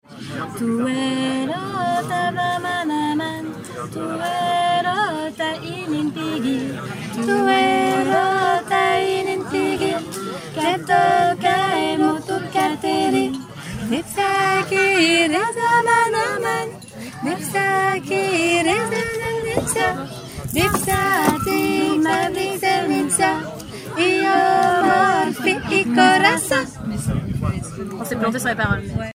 À écouter ci-dessous, extrait des 3 voix une à une…
Ksenitiatouerota-soprane.mp3